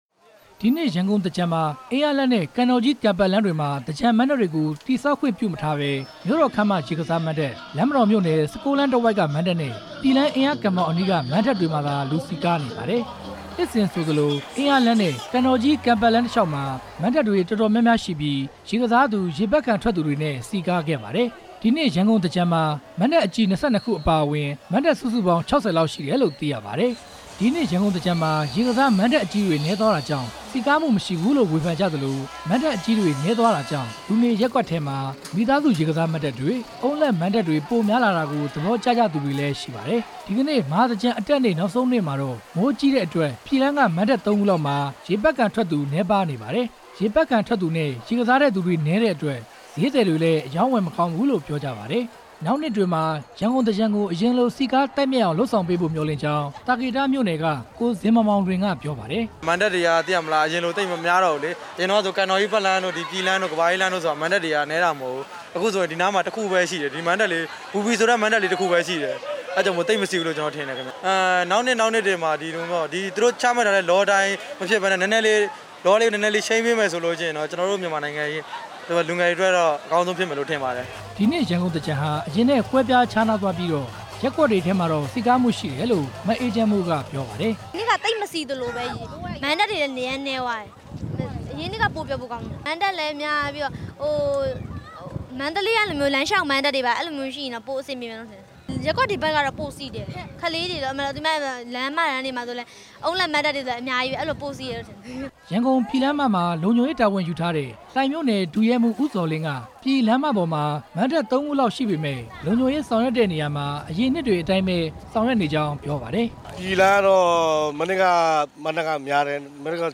ရန်ကုန်သူ ရန်ကုန်သားတွေရဲ့ စကားသံတွေကို